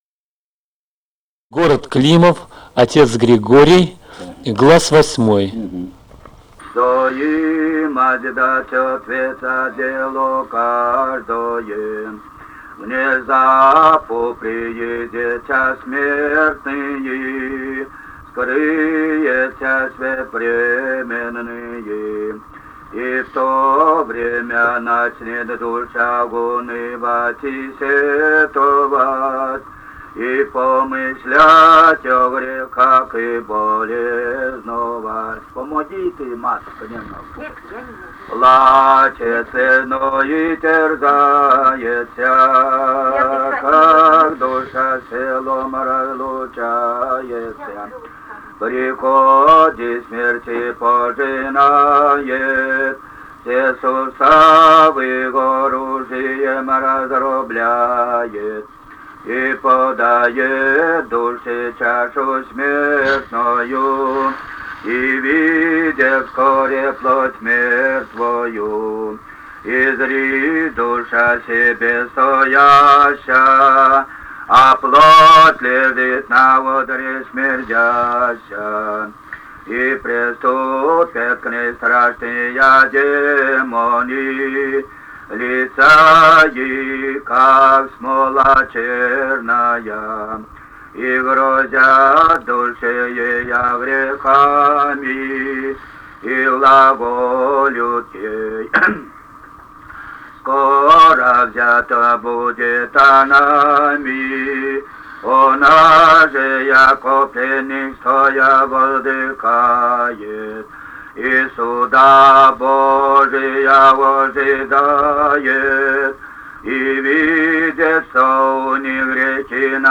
Записали участники экспедиции